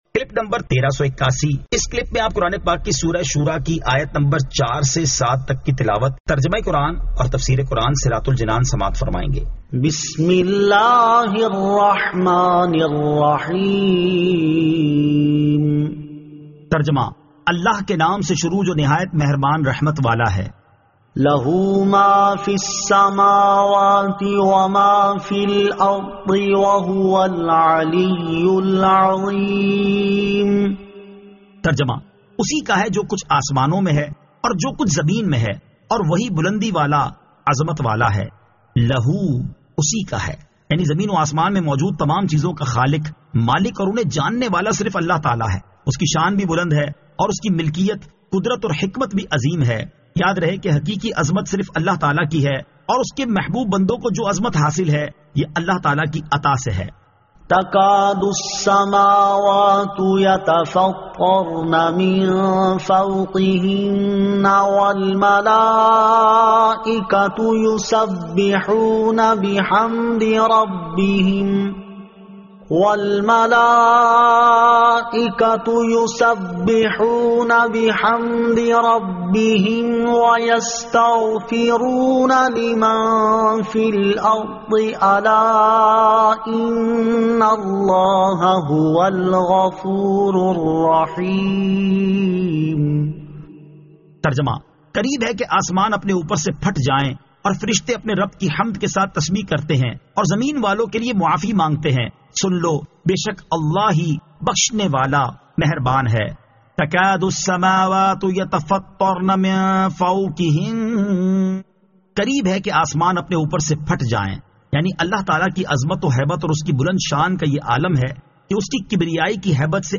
Surah Ash-Shuraa 04 To 07 Tilawat , Tarjama , Tafseer